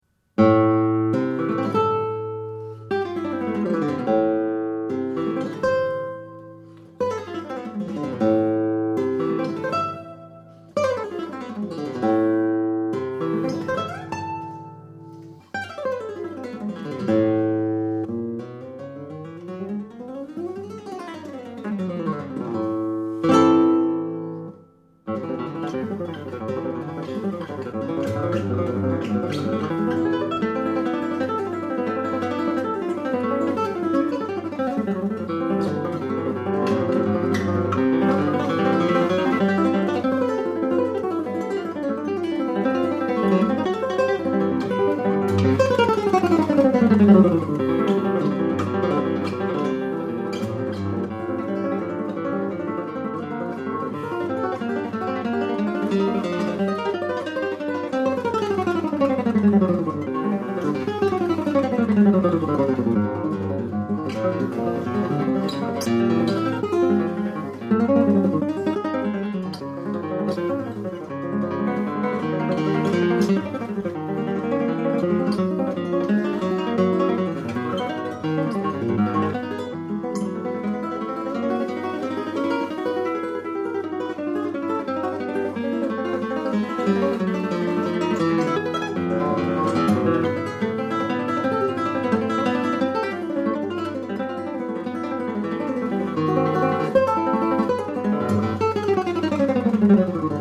Works for Guitar